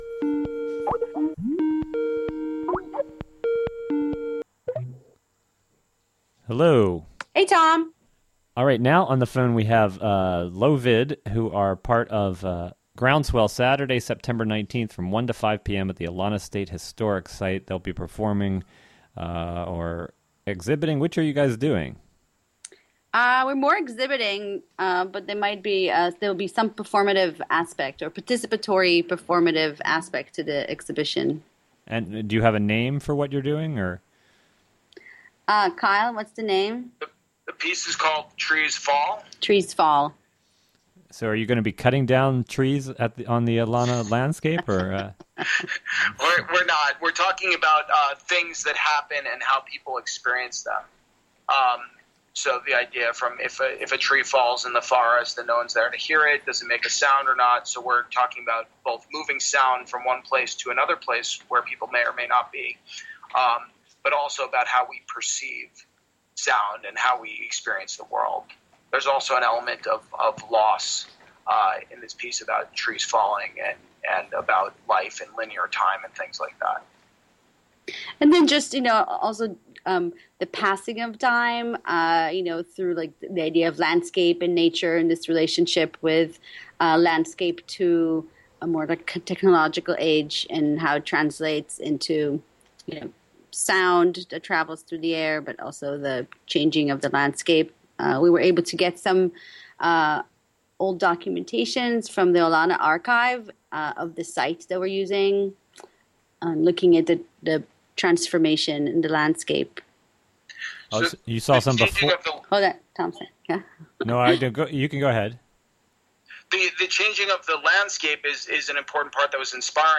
Interviewed